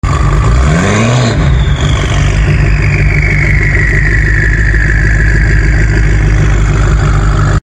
😈 BMW M8 & M5 Sound Effects Free Download